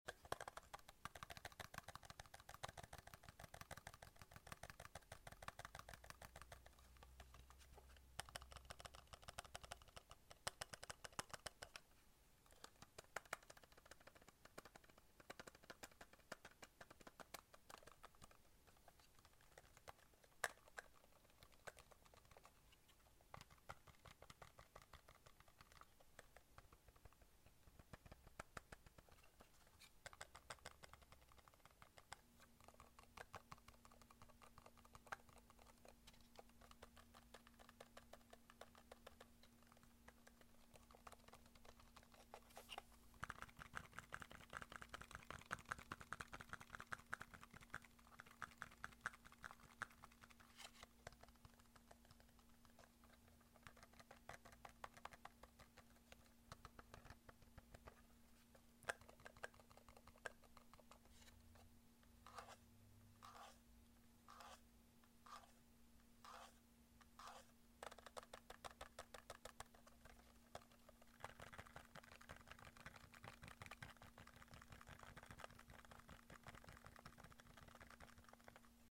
Fast remote tapping and scratching sound effects free download